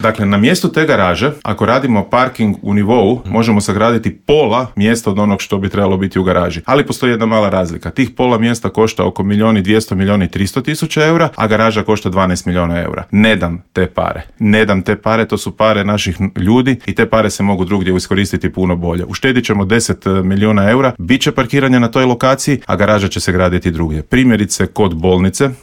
Borba se vodi i u Gradu Puli gdje poziciju čelnog čovjeka želi bivši predsjednik SDP-a i saborski zastupnik Peđa Grbin koji je u Intervjuu Media servisa poručio: